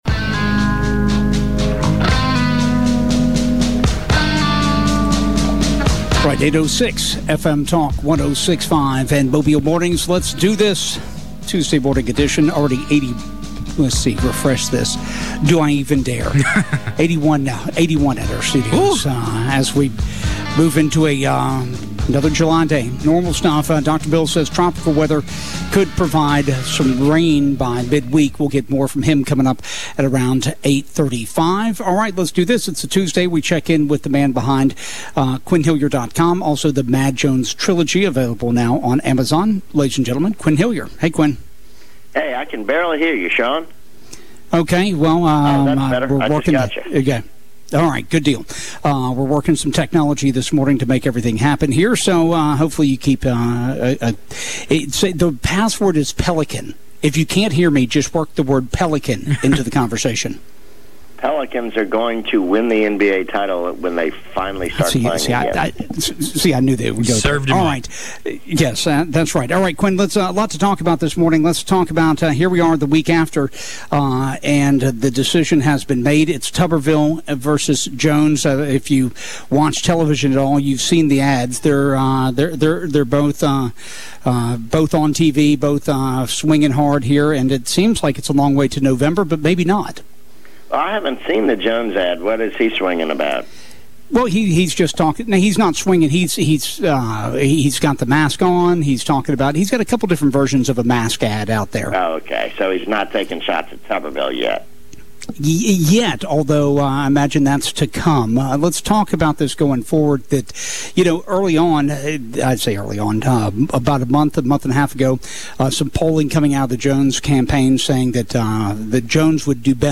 report on local news and sports.
reports on traffic conditions. Other subjects include new testing guidlines, cases of covid.